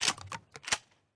Shotgun_Reload.ogg